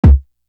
Up High kick.wav